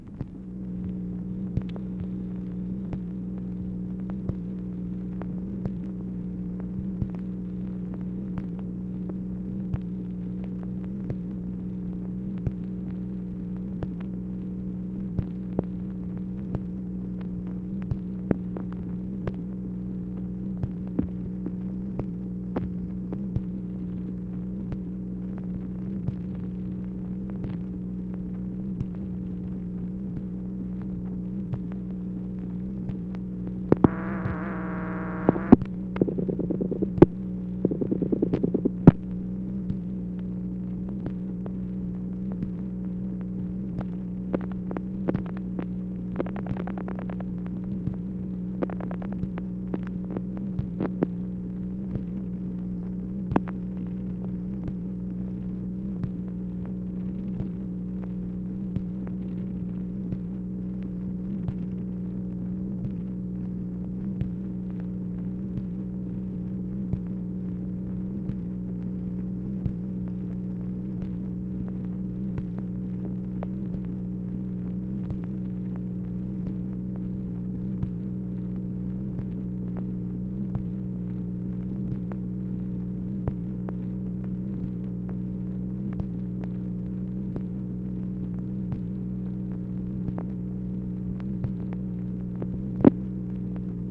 MACHINE NOISE, January 29, 1964
Secret White House Tapes | Lyndon B. Johnson Presidency